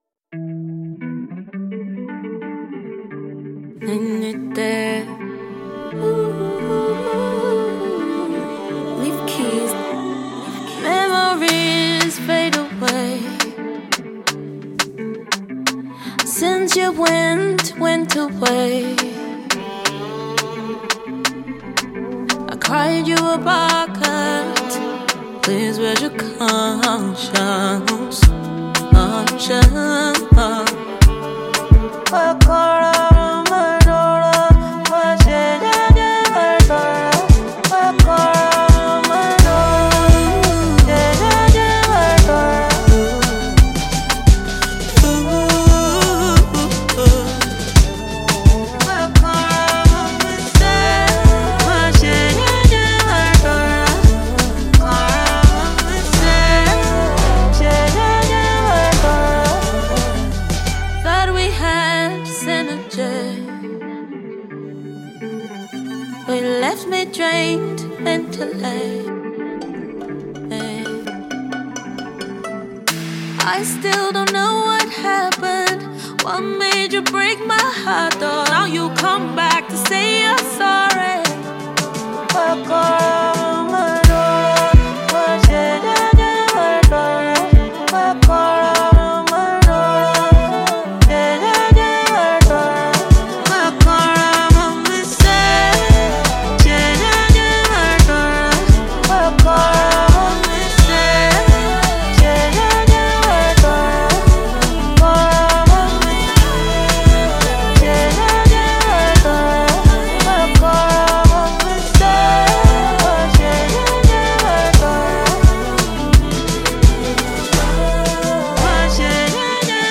afro-pop
violin